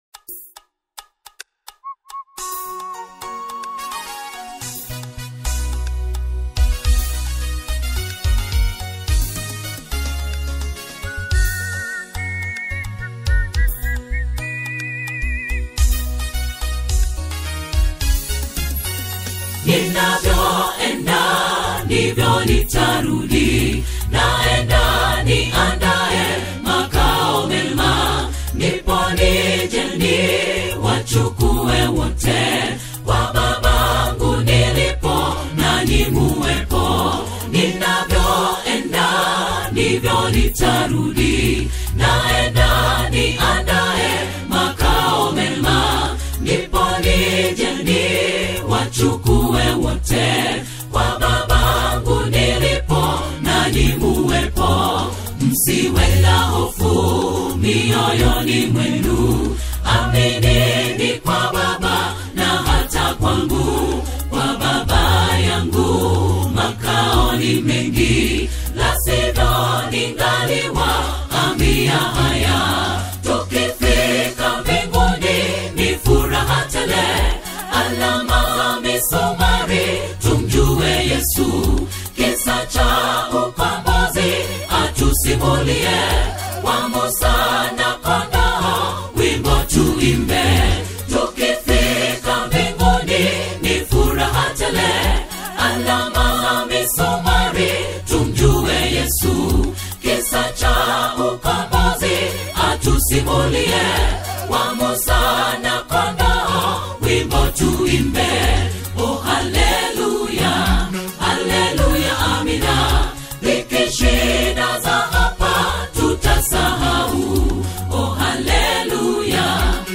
The choral and worship landscape
a deeply reflective new single